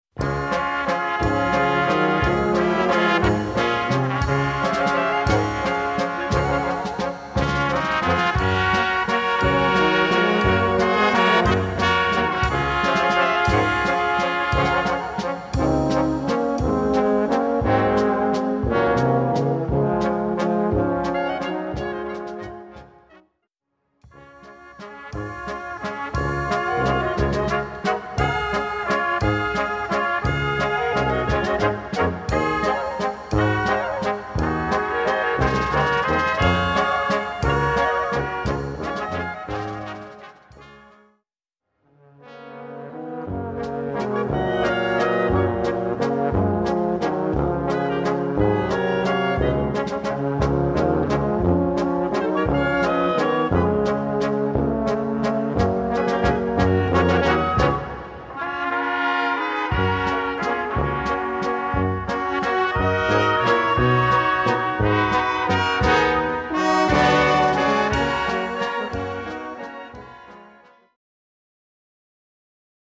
Gattung: Walzer
A5-Quer Besetzung: Blasorchester PDF